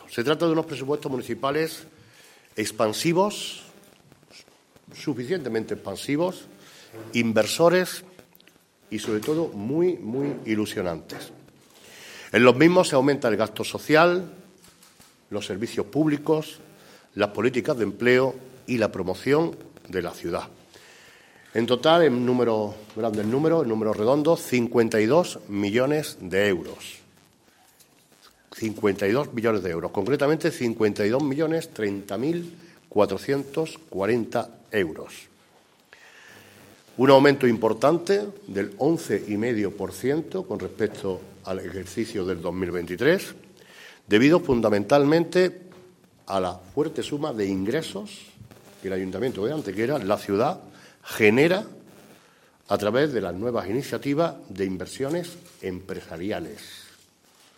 El alcalde de Antequera, Manolo Barón, ha presentado hoy ante los medios de comunicación las líneas maestras de los Presupuestos Generales del Ayuntamiento de Antequera para el ejercicio económico 2024, en una rueda de prensa junto al teniente de alcalde delegado de Hacienda, Antonio García Acedo y el resto de miembros del Equipo de Gobierno del Ayuntamiento de Antequera.
Cortes de voz